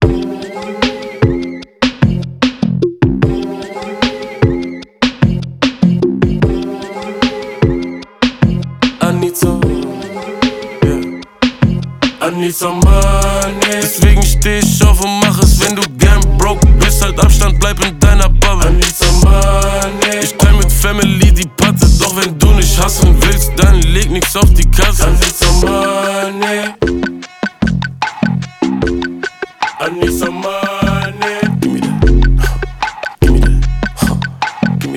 Жанр: Иностранный рэп и хип-хоп / Рэп и хип-хоп